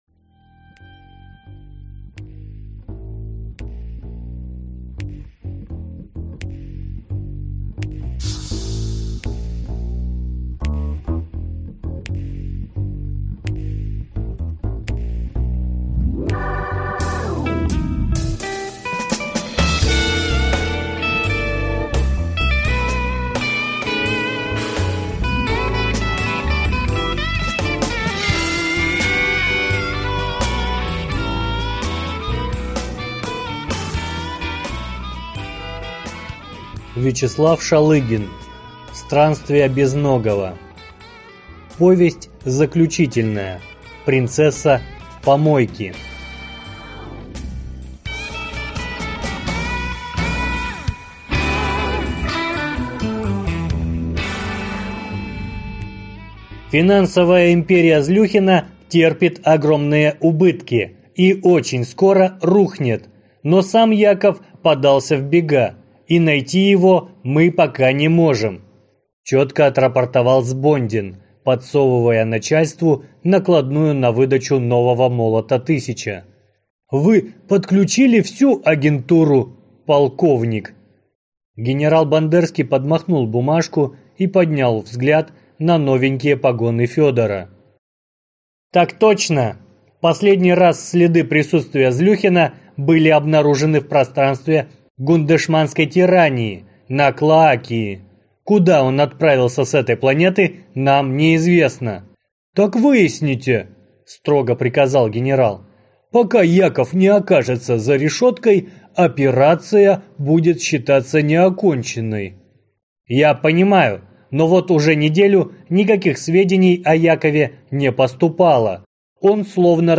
Аудиокнига Принцесса помойки | Библиотека аудиокниг